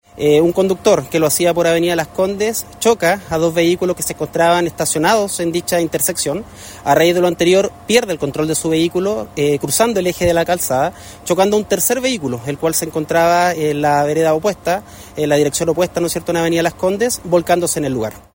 carabinero.mp3